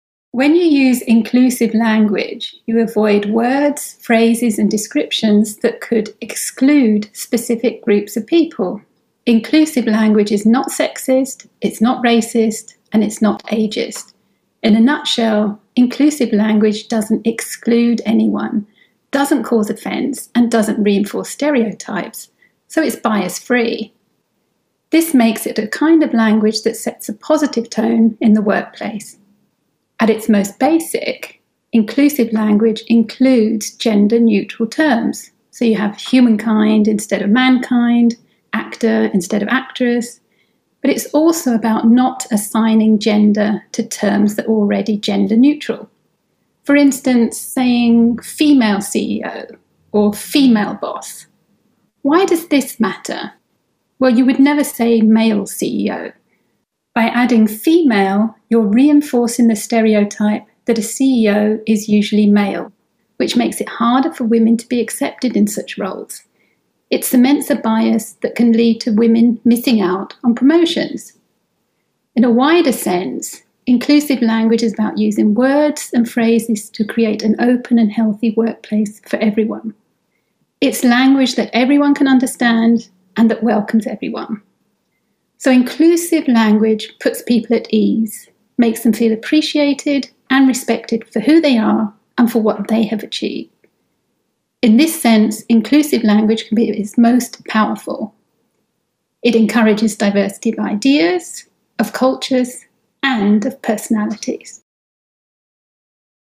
Business Skills - Interview